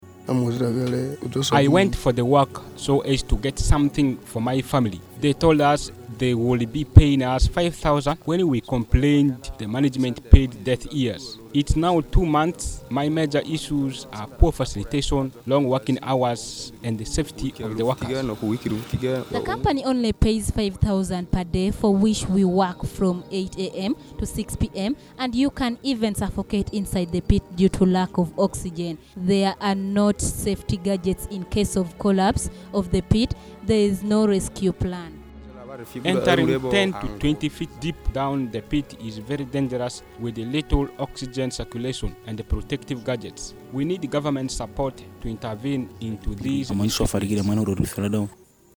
Voices of some of the workers.mp3